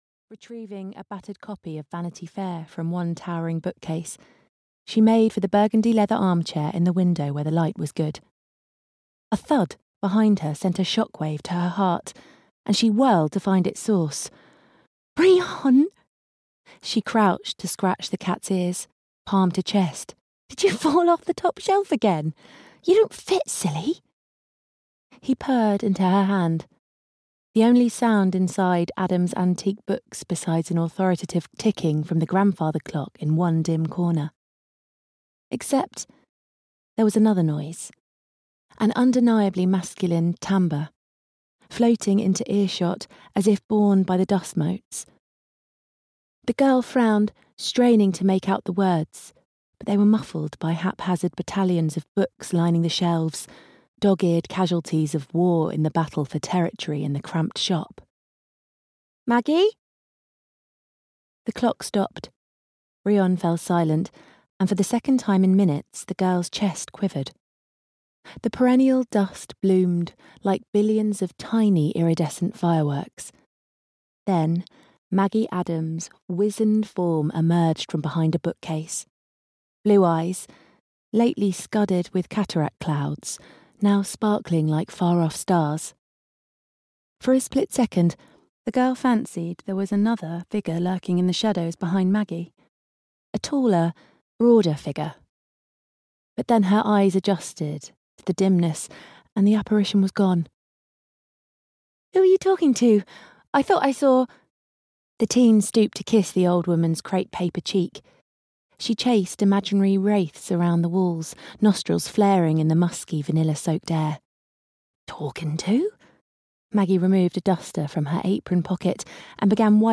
Audio knihaThe Book Boyfriend (EN)
Ukázka z knihy